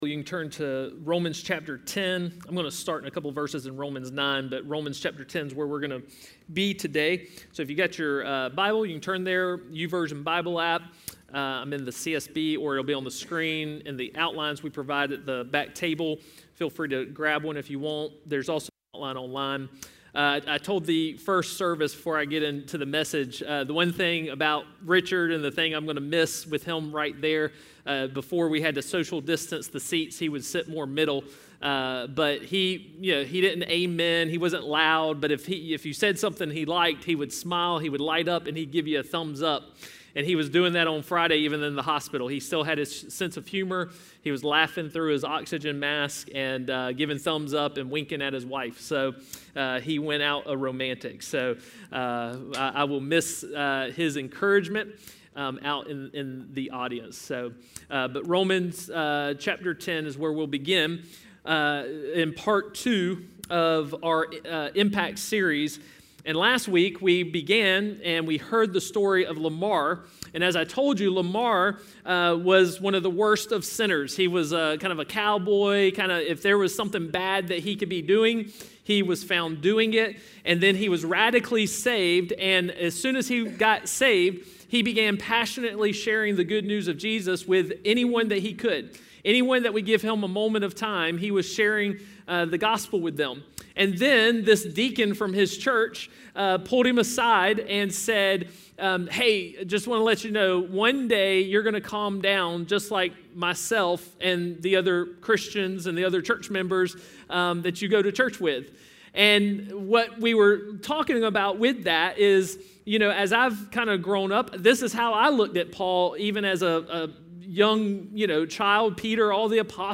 A message from the series "Disciple."